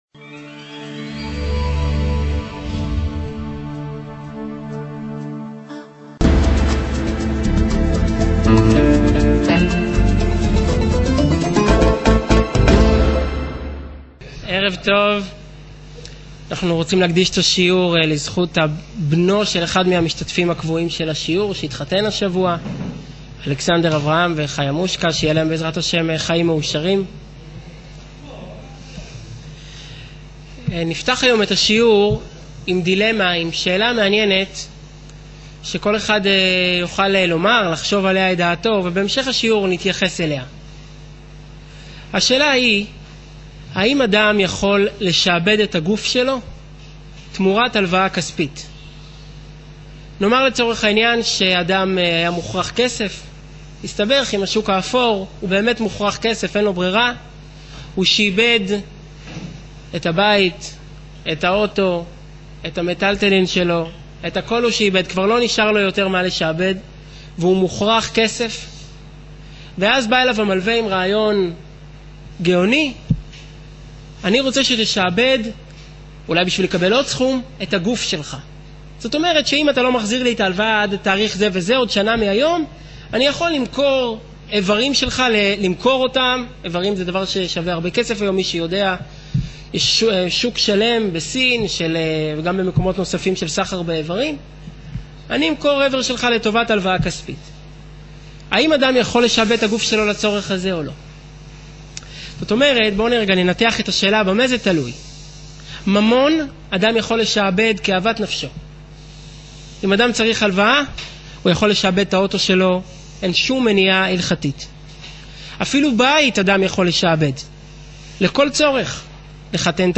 בואו לשמש כשופטים: מהי בעלות האדם על גופו? ● שיעור וידאו